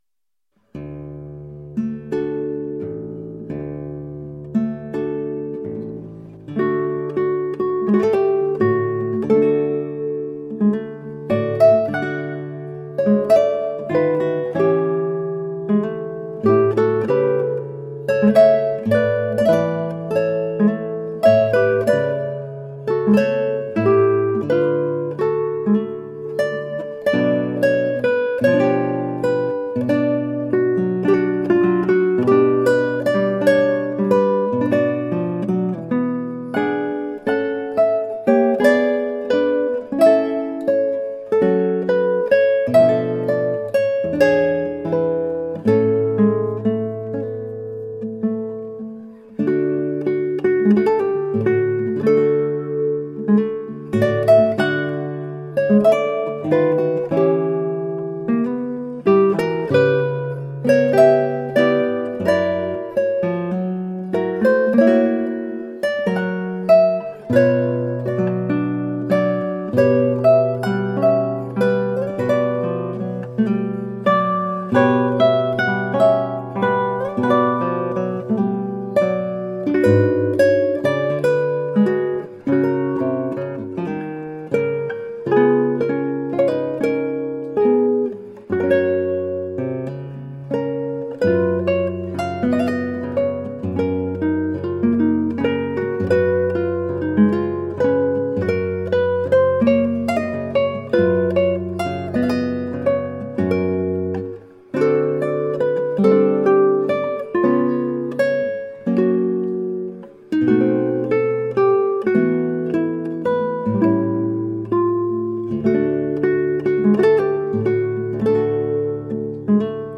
Colorful classical guitar.
It is highly expressive and gorgeously sensuous.
Instrumental
Classical Guitar